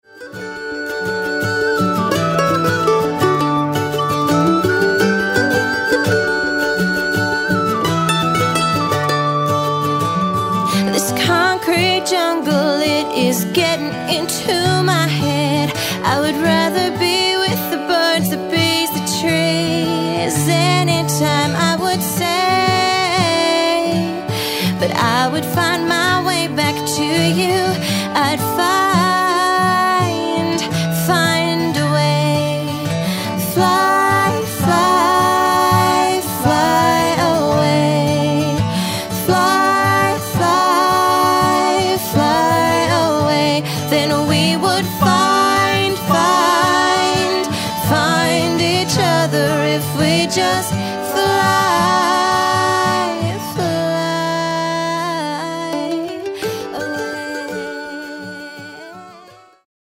celtic inspired songs